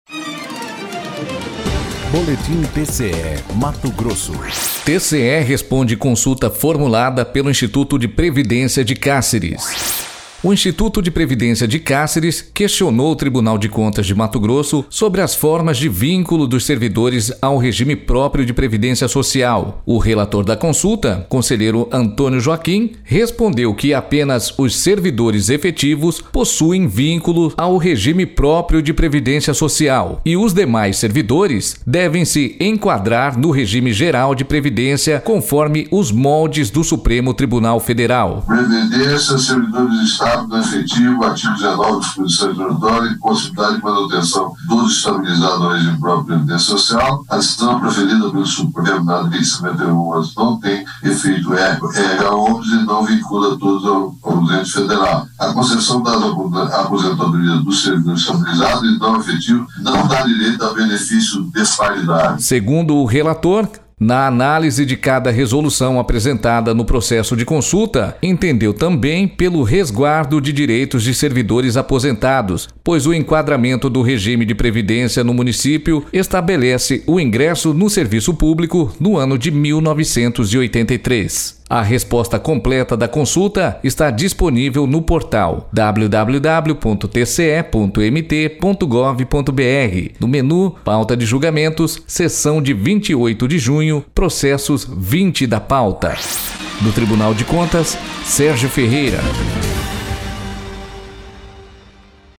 Sonora: Antonio Joaquim – conselheiro do TCE-MT